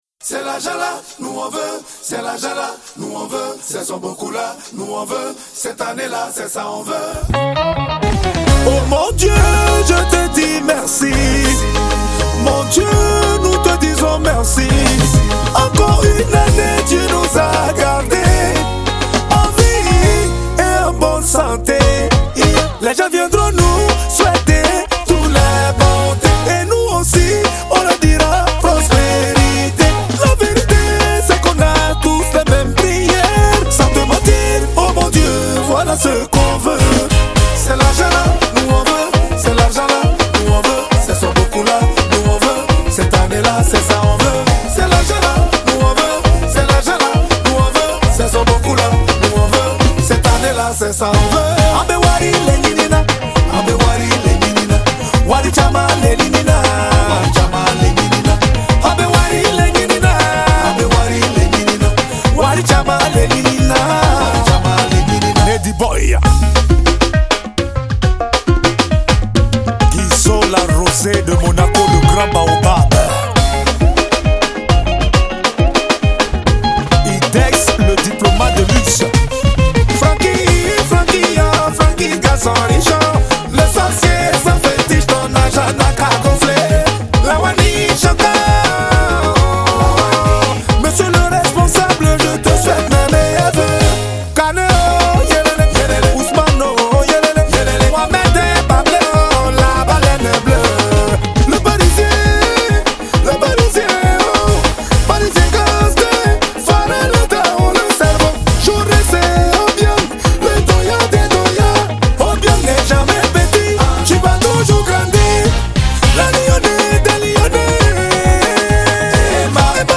| Coupé décalé